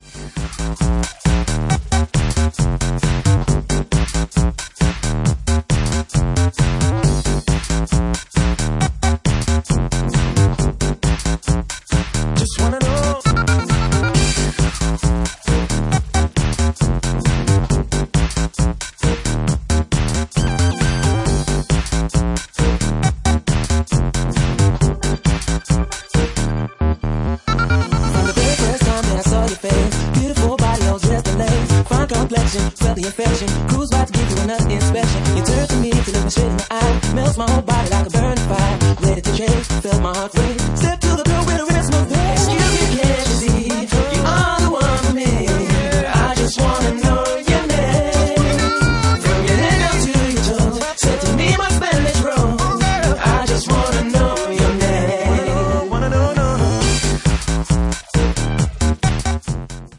Genre:4x4 / Garage
4x4 / Garage at 135 bpm